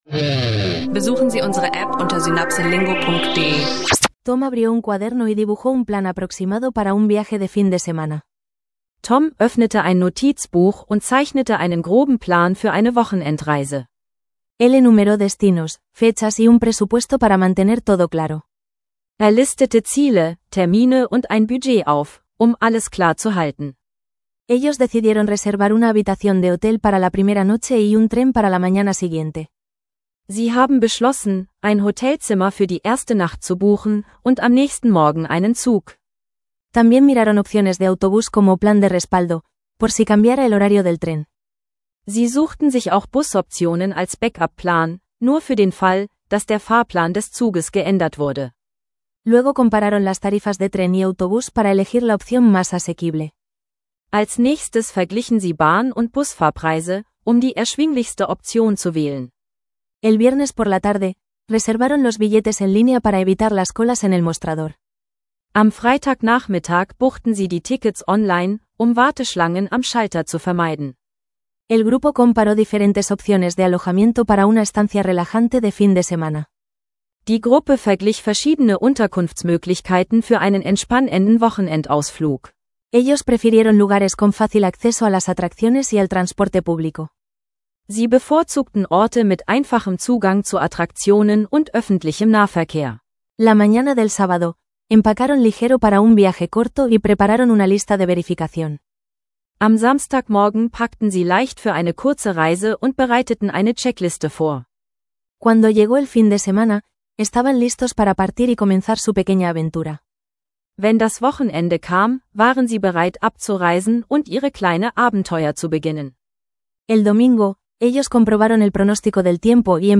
Lerne Spanisch mit praxisnahen Dialogen zu Planungen einer